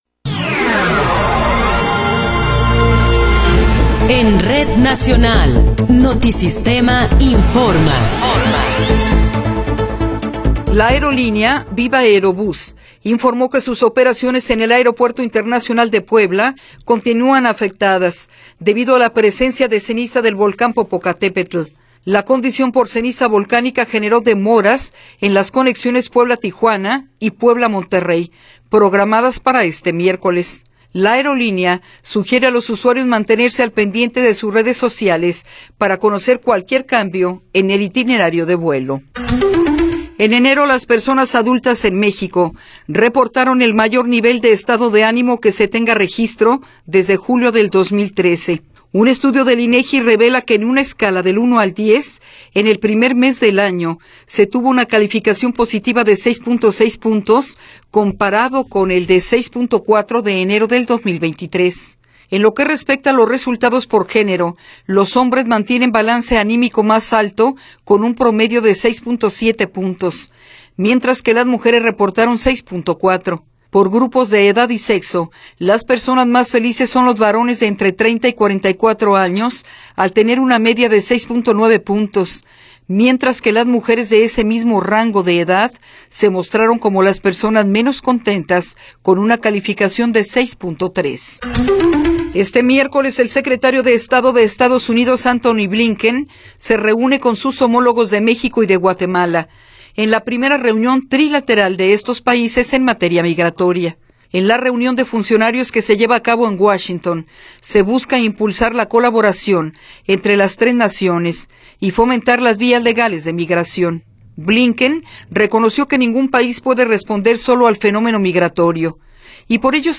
Noticiero 10 hrs. – 28 de Febrero de 2024
Resumen informativo Notisistema, la mejor y más completa información cada hora en la hora.